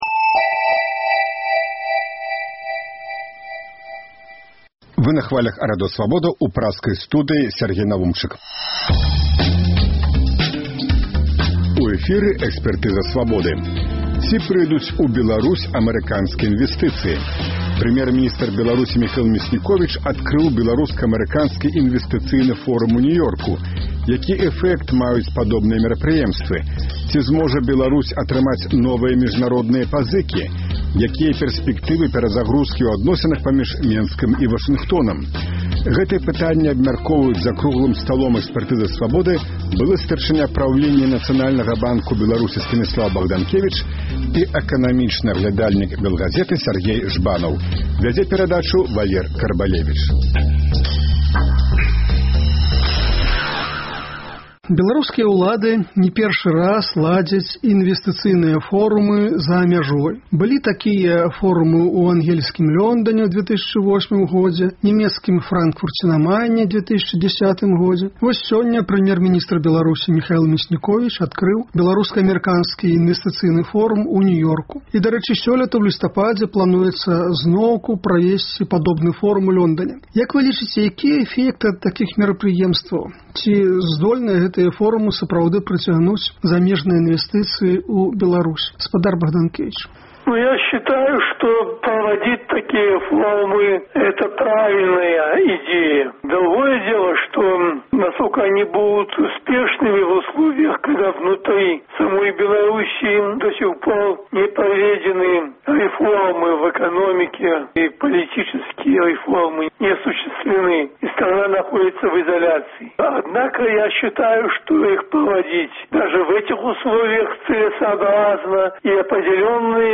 Ці зможа Беларусь атрымаць новыя міжнародныя пазыкі? Якія пэрспэктывы перазагрузкі ў адносінах паміж Менскам і Вашынгтонам? Гэтыя пытаньні абмяркоўваюць за круглым сталом «Экспэртызы «Свабоды»